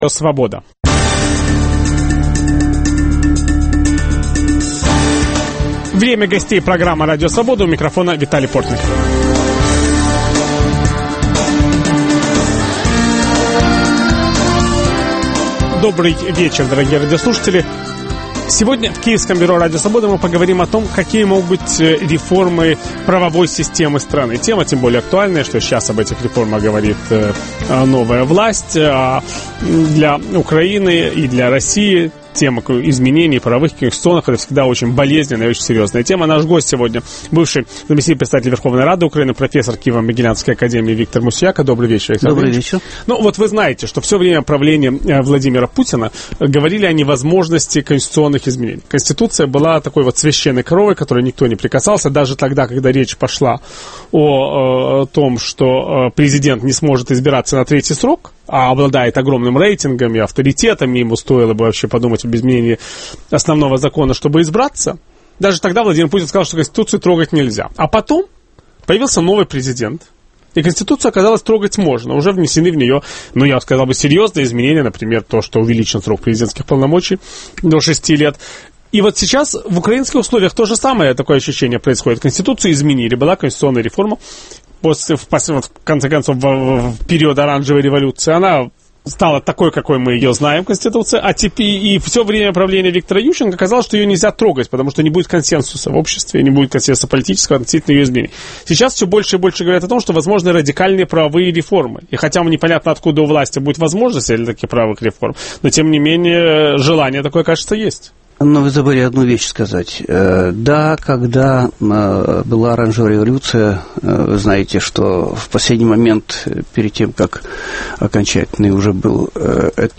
Зачем украинской власти судебная реформа? В программе участвует: бывший заместитель председателя Верховной рады Украины профессор Виктор Мусияка.